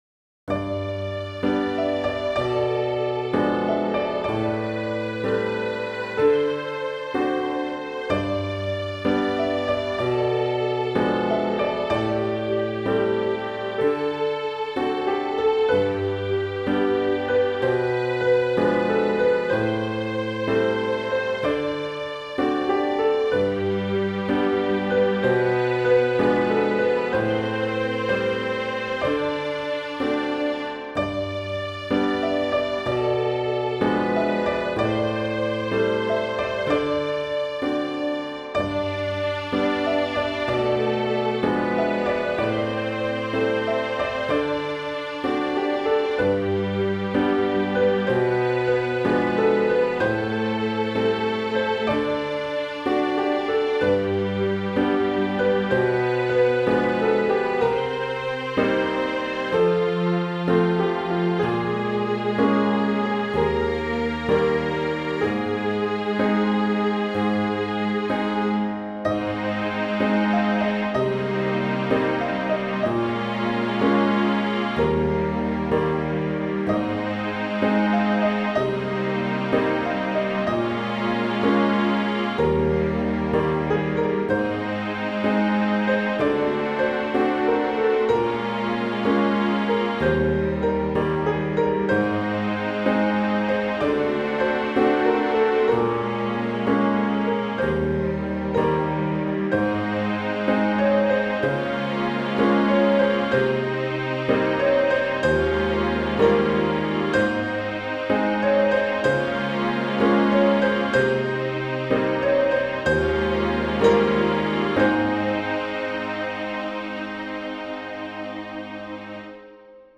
Balada orquestal
balada
melodía
orquestal